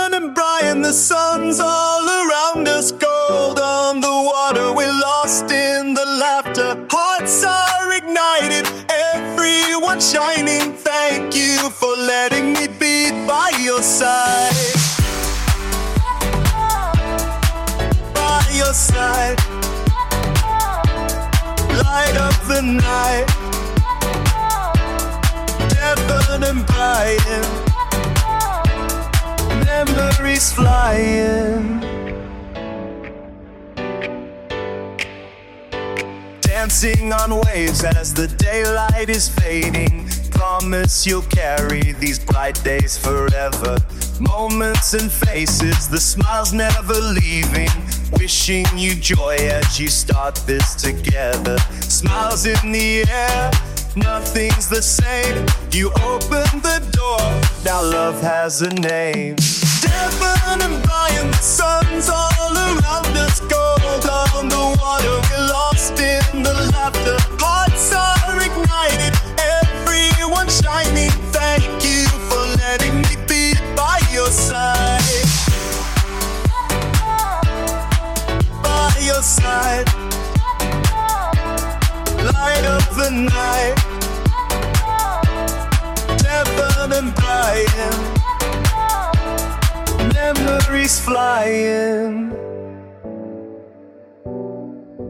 AI music created in the MusicKraze iPhone app
Tags: Edm